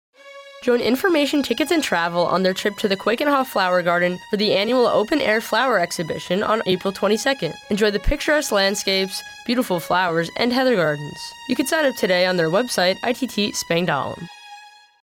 This 30 second radio spot discusses Information Tickets and Travel offices trip to the Keukenhof Flower Garden